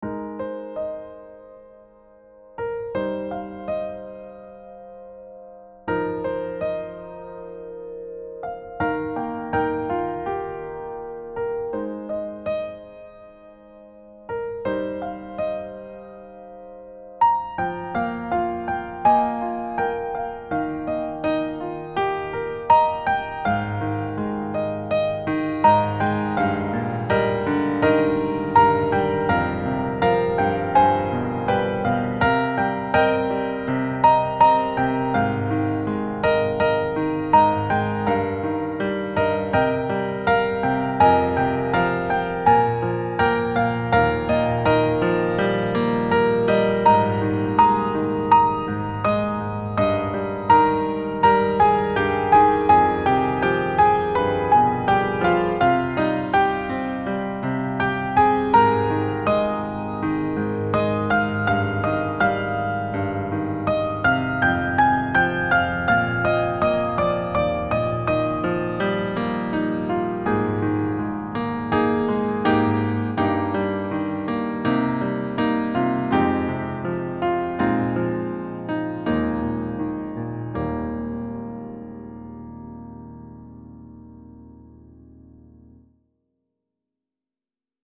未分類 バラード ピアノ 切ない 懐かしい 穏やか 音楽日記 よかったらシェアしてね！